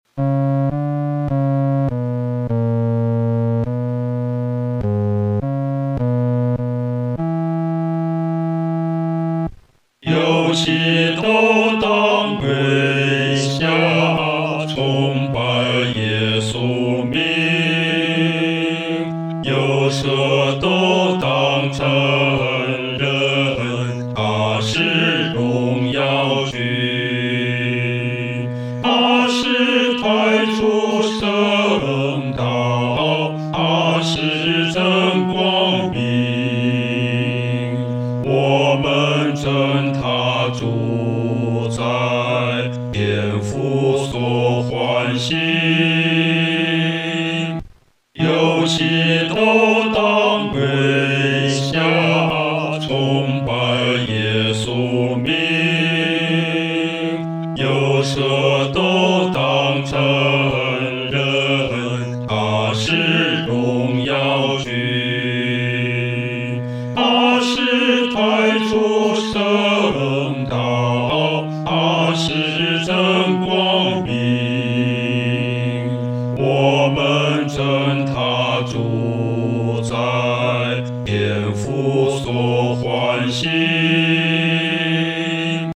男低
这首诗歌宜用中庸的速度来弹唱。